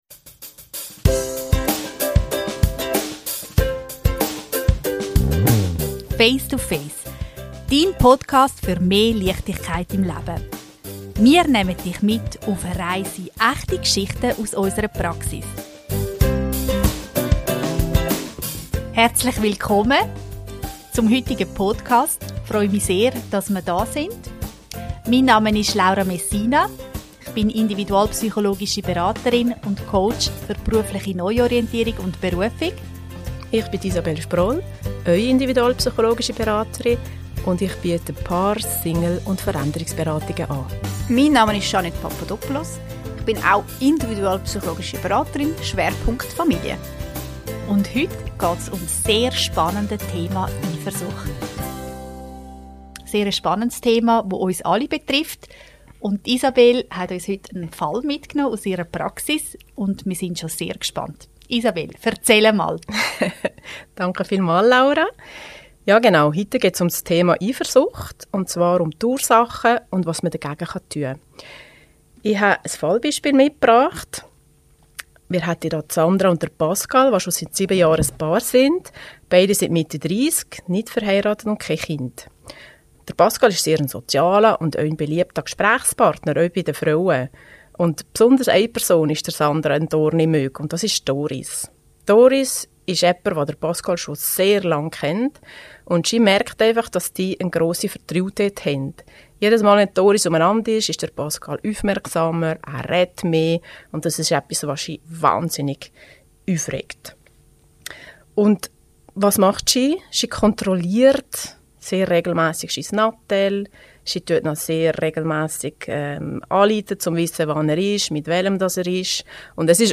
In dieser Folge sprechen die drei individualpsychologischen Beraterinnen